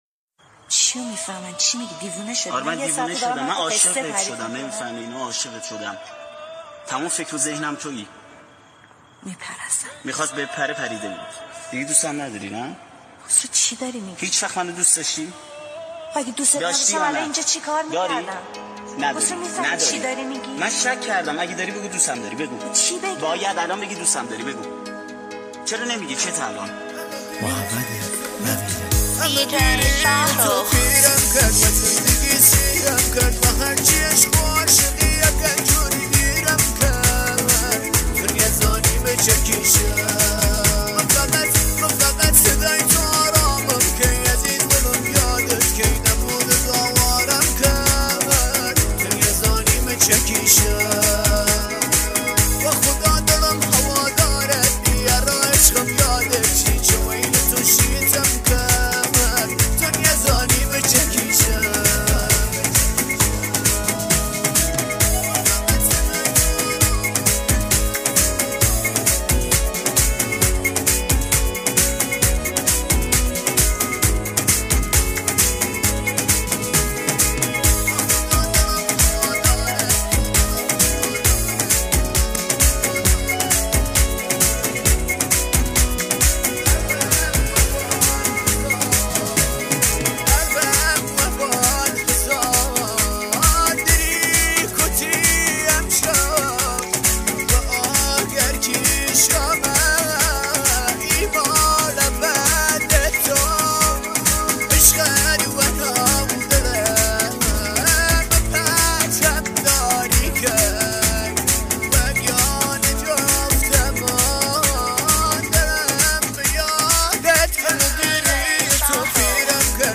ریمیکس
🎹 آهنگ دیسلاو کردی مخصوص 🎹